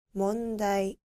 • もんだい
• mondai